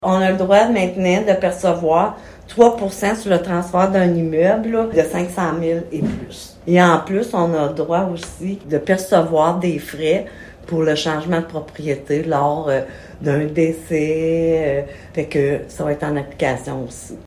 L’objectif est d’augmenter les revenus de la Ville afin d’être en mesure de répondre aux besoins grandissants de la population en termes de services. La mairesse, Francine Fortin, en dit davantage sur ce nouveau palier d’imposition :